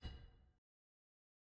sfx-uikit-arena-modal-hover.ogg